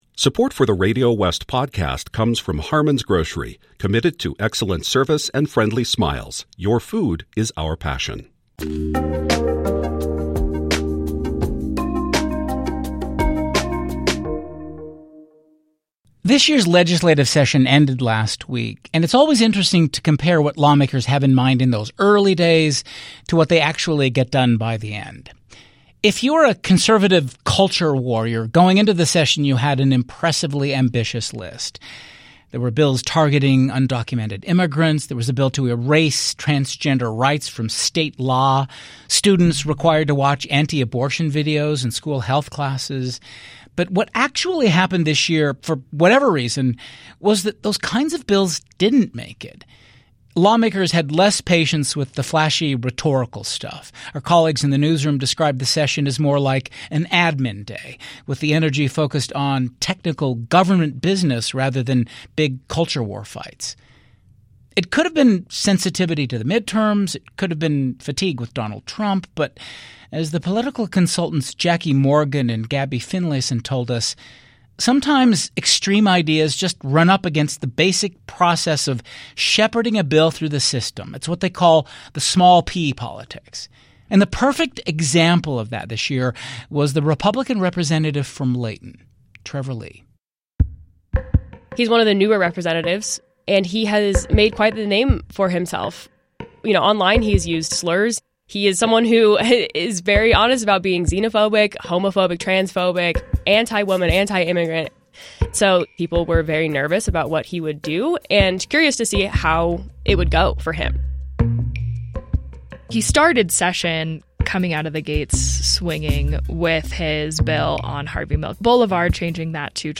The 2026 Utah Legislative Session concluded last week. We’re gathering a trio of reporters to talk about what passed and what failed.